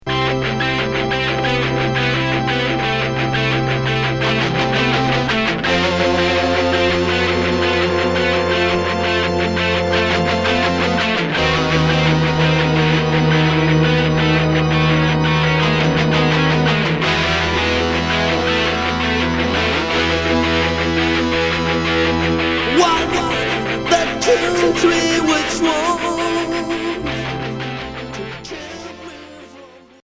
Heavy métal